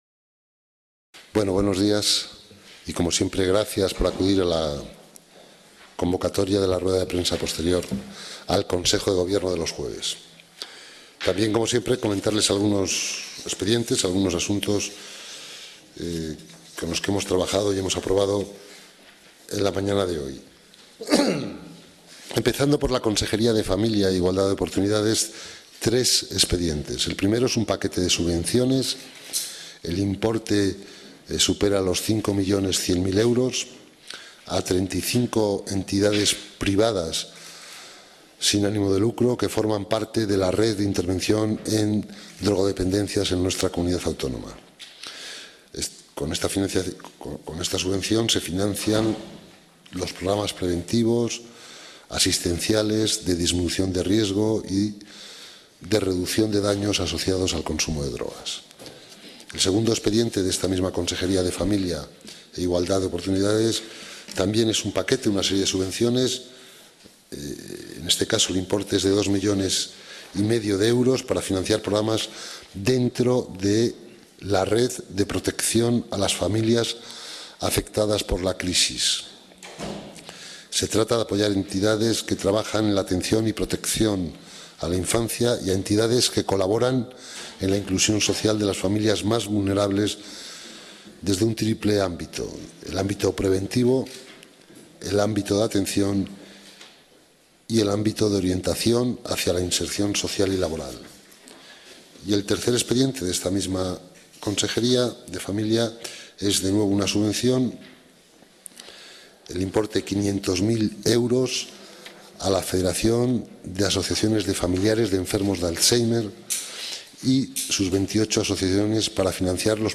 Audio Rueda de Prensa CG.